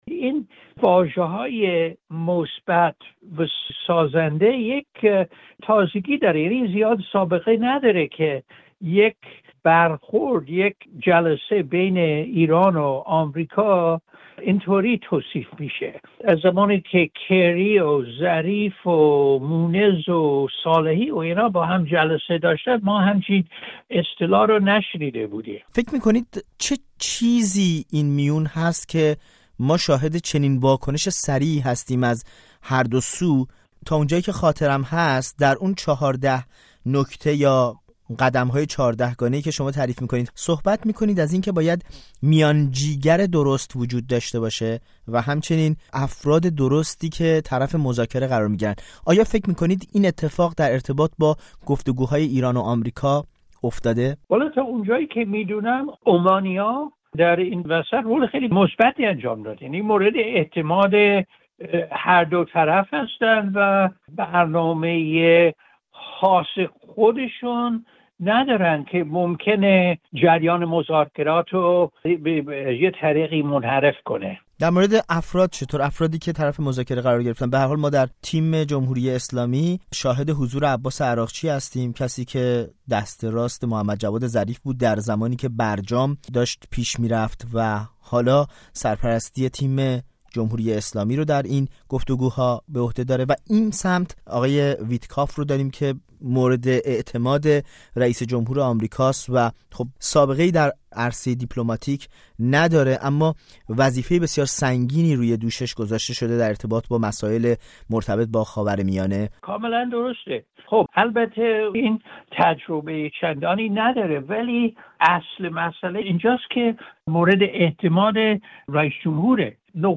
گفت‌وگو با جان لیمبرت دربارۀ مذاکرات ایران و آمریکا